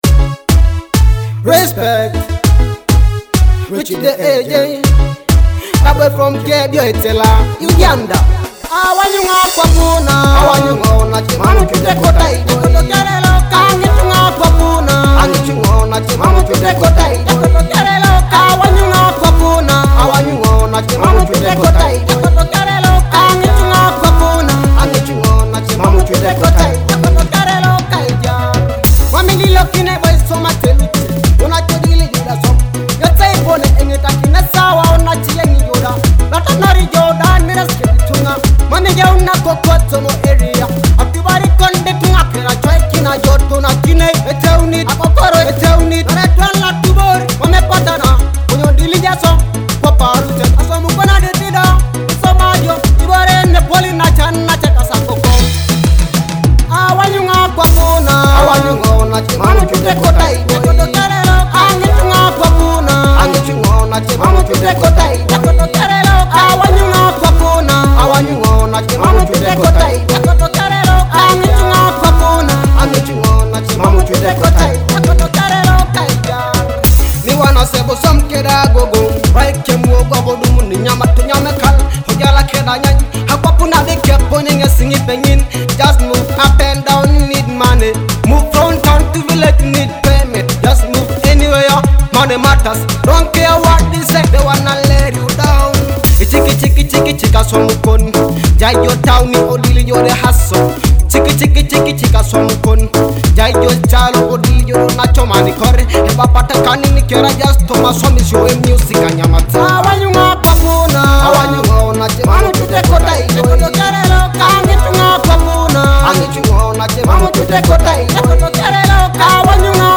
an inspiring Teso hit about hard work
vibrant sounds of Teso music
With infectious rhythms and heartfelt messages